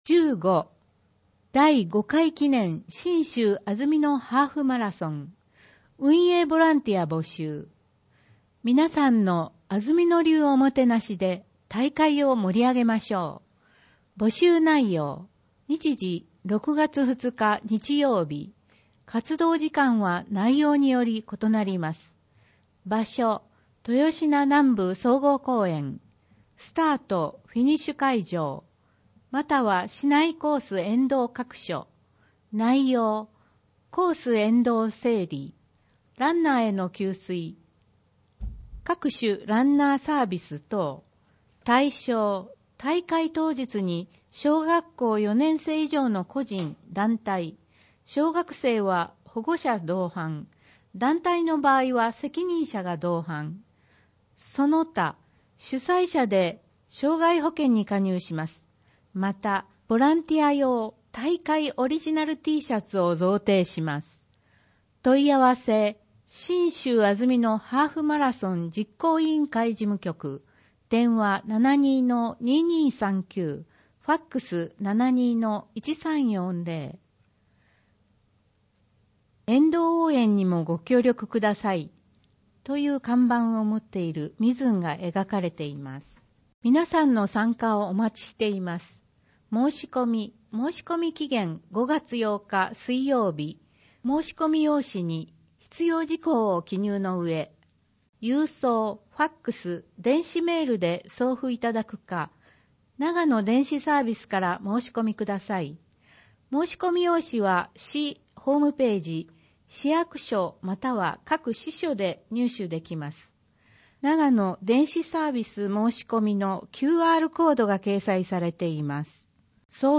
広報あづみの朗読版286号（平成31年2月20日発行)
「広報あづみの」を音声でご利用いただけます。この録音図書は、安曇野市中央図書館が制作しています。